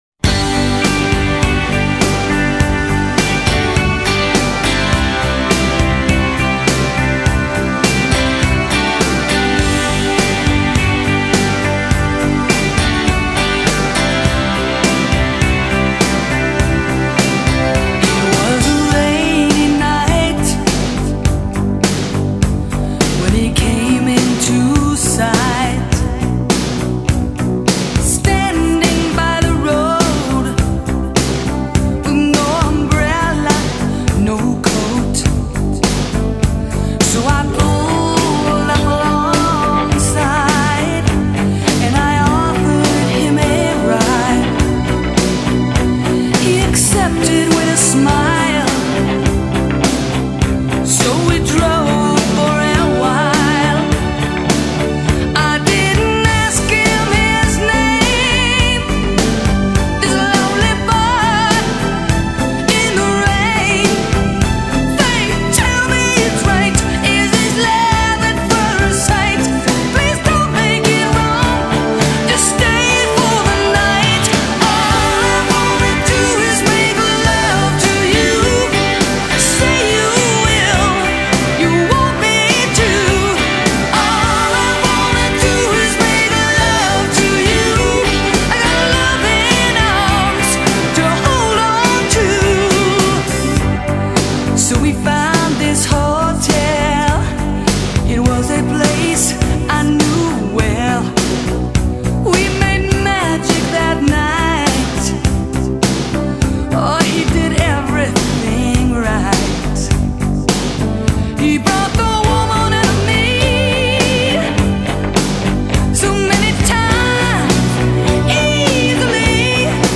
女性摇滚团体